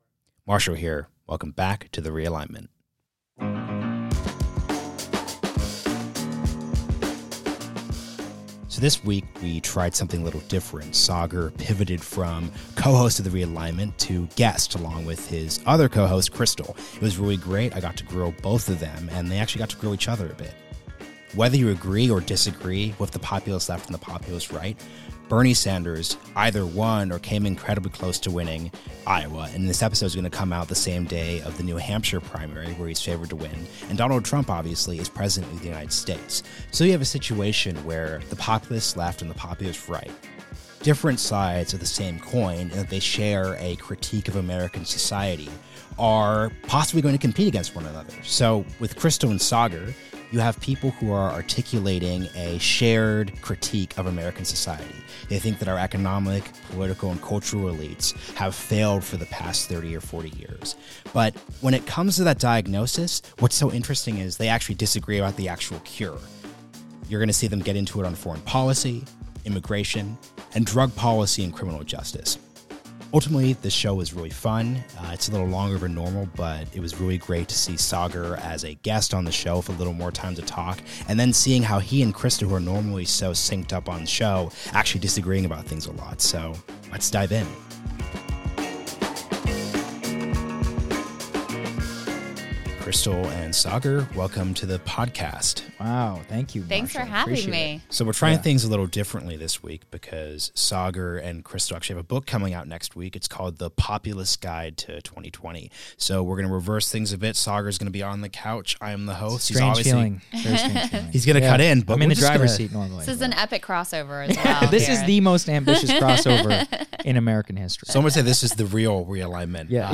News Commentary
Hill Rising co-hosts Krystal Ball and Saagar Enjeti join The Realignment to articulate the case for the populist left and right.